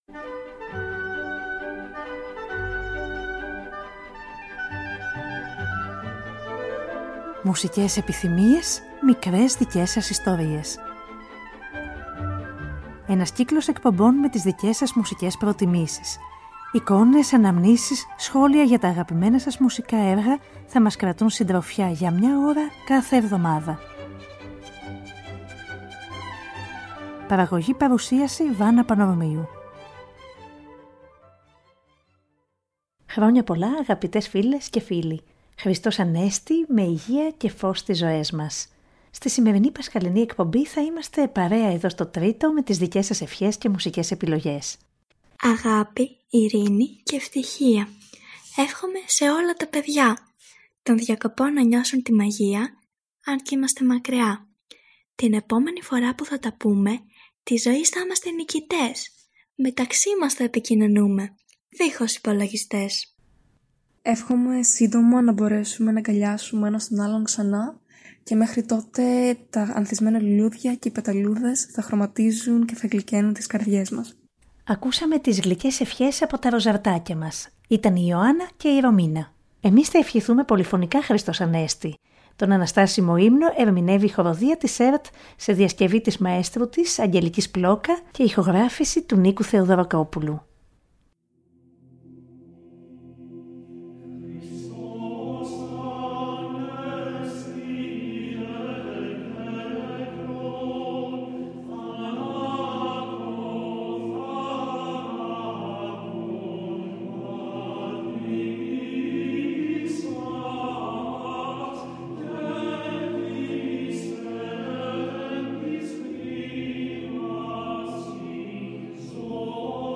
Σε αυτή την εκπομπή έχουν την τιμητική τους οι χορωδίες.